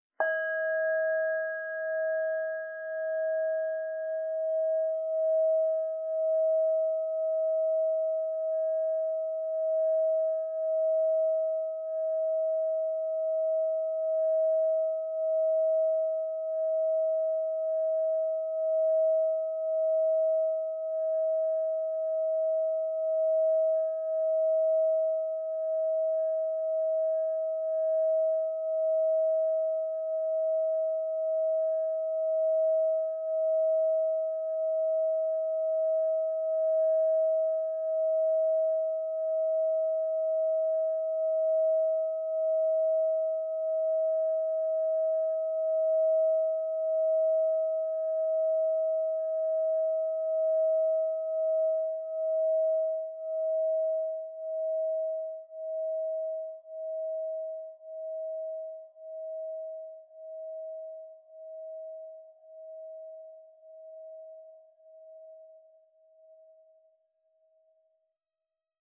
Meinl Sonic Energy 4.25" Essence Solfeggio Crystal Singing Bowl Fa 639 Hz, Indigoblau (ESOLCSB639)